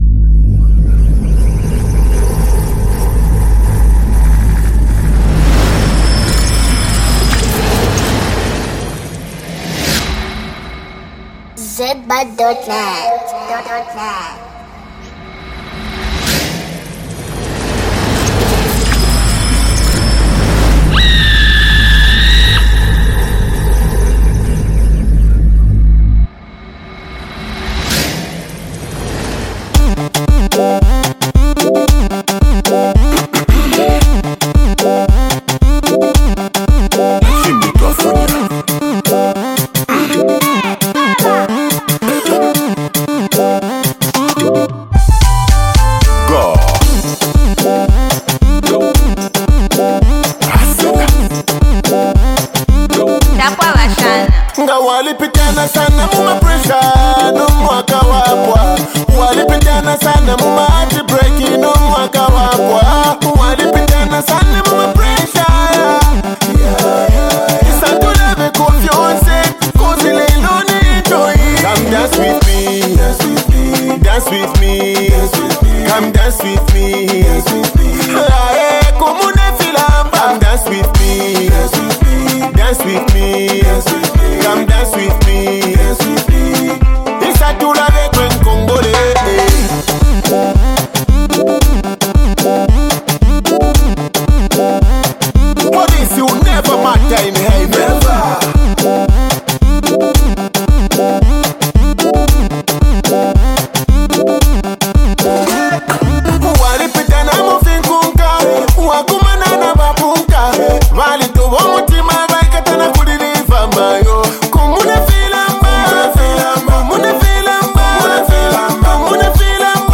with its harmonious layers and infectious rhythm.